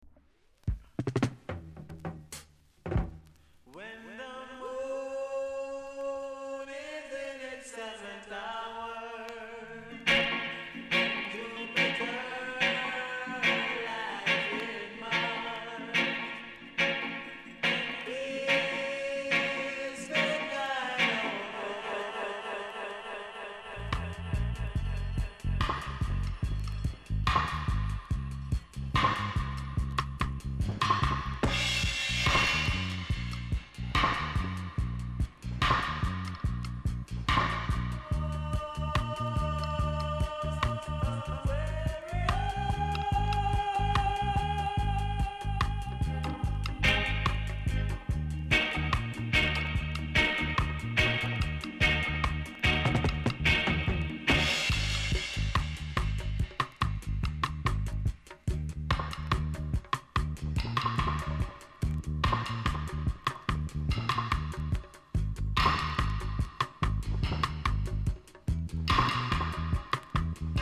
※小さなチリノイズが少しあります。